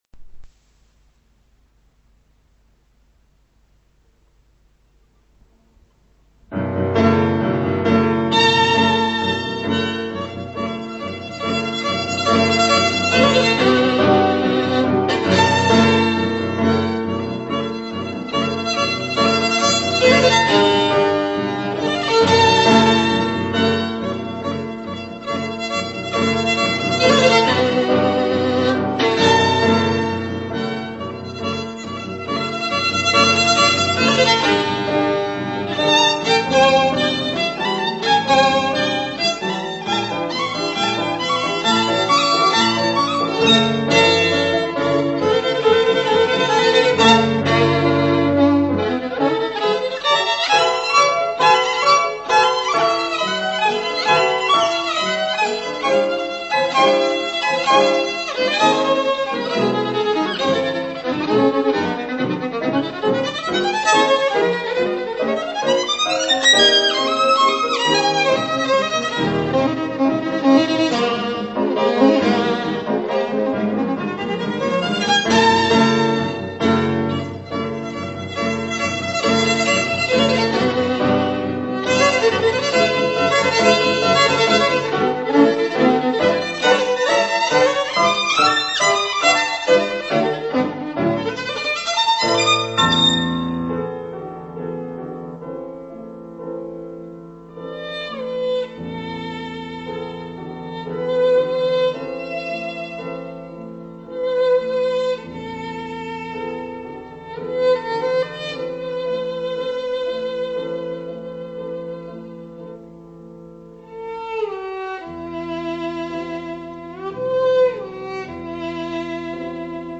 以欢快曲调表现农民欢快丰收的情景，副主题以缓慢、优美的曲调
经过两次反复后，曲尾在热烈欢快的气氛中结束。